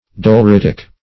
doleritic - definition of doleritic - synonyms, pronunciation, spelling from Free Dictionary
Doleritic \Dol`er*it"ic\, a.